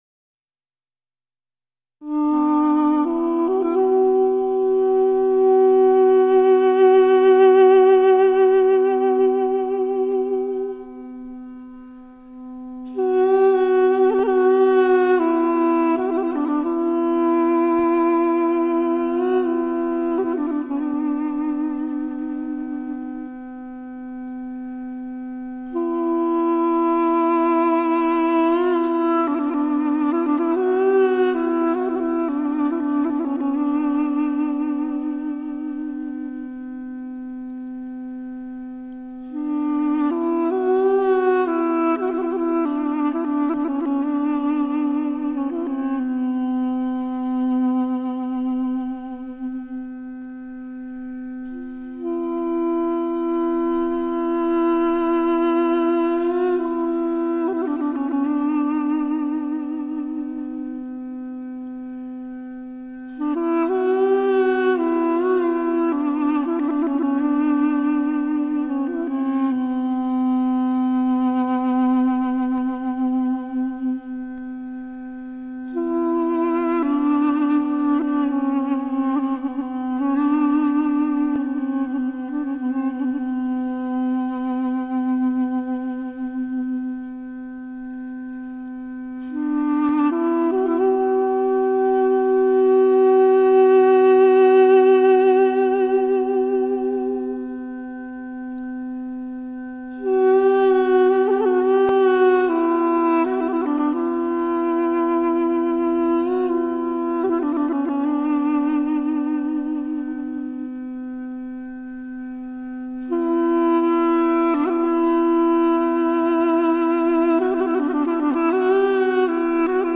Настроение: заунывно
Музыка: дудук
в его звуках душа армянского народа.